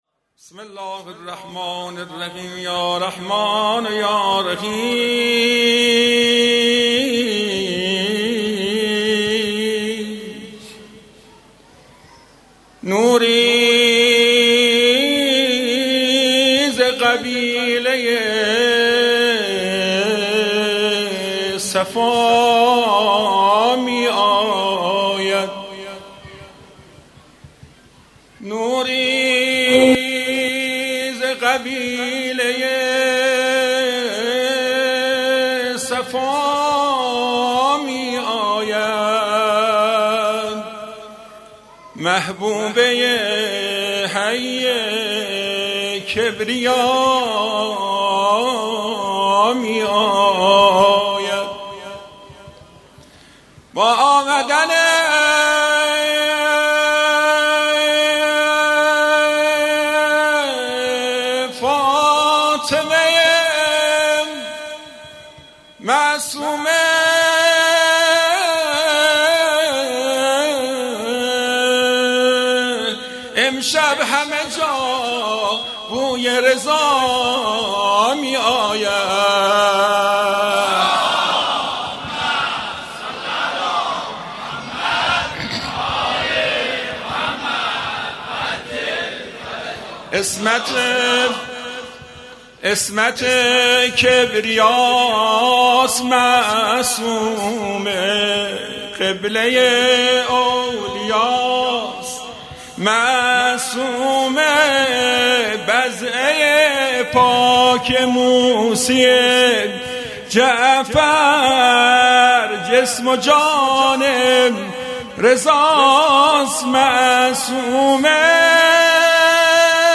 خیمه گاه - هیئت انصار ولایت دارالعباده یزد - هفتگی 15 تیر 98 - شعرخوانی خادمین حرم رضوی